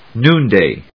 /núːndèɪ(米国英語)/